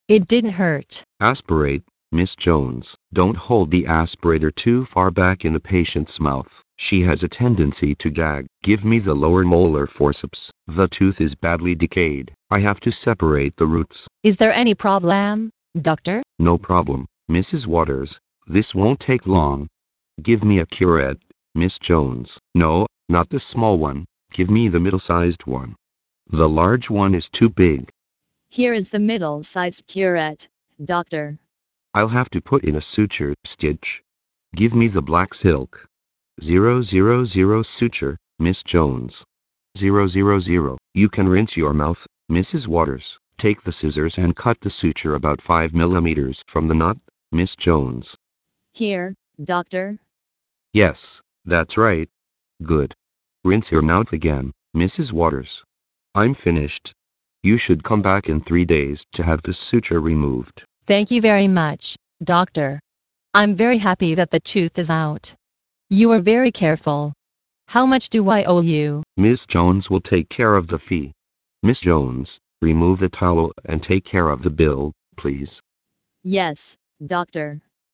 收录了口腔医学医患、医助之间的对话，非常适合医学生、临床医务人员练习专业口语和听力，在欧洲很受欢迎。